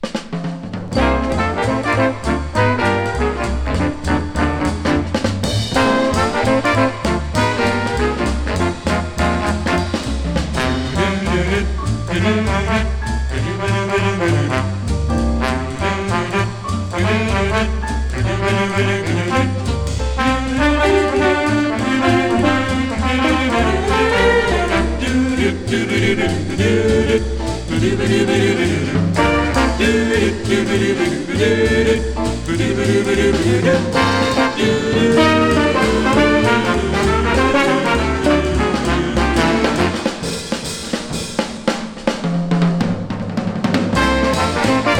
スウィング感と洒落たアレンジも魅力たっぷり。
Jazz, Stage & Screen　USA　12inchレコード　33rpm　Mono